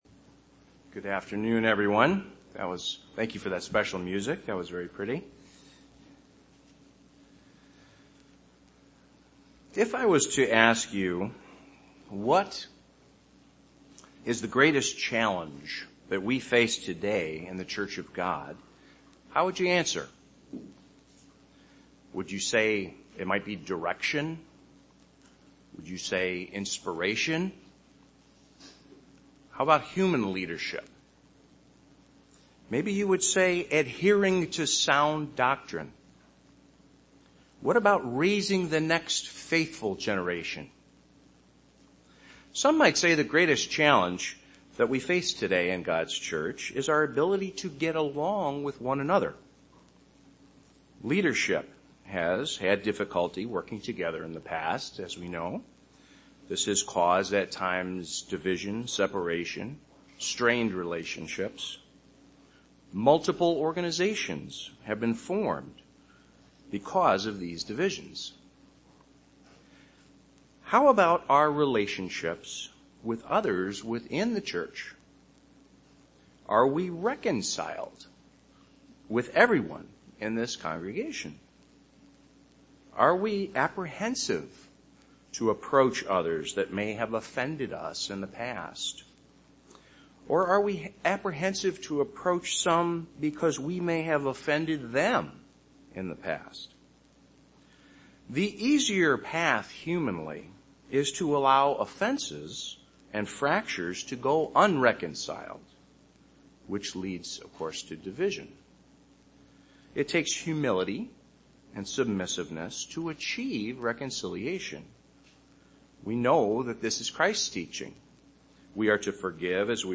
Given in Chicago, IL
UCG Sermon unity Unity of the church Studying the bible?